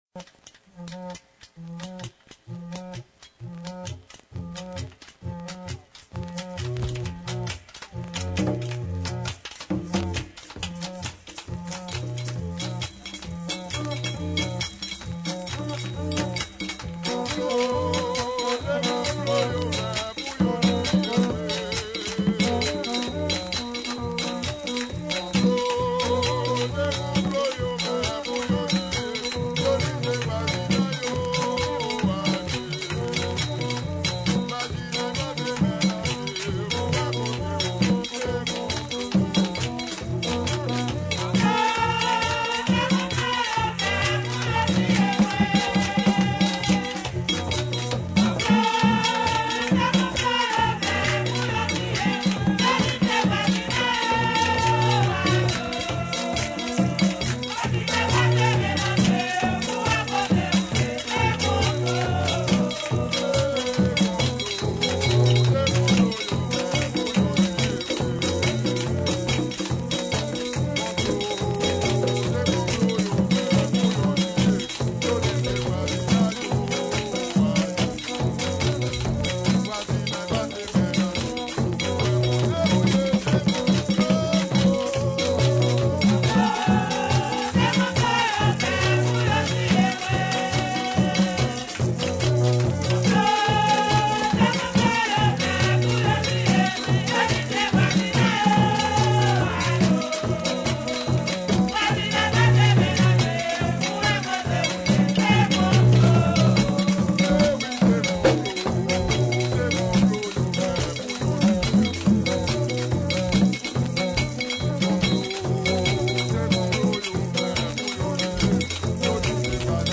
2022 RARA D'HAITI (VACCINE, FLOKLORE HAITIEN) audio closed https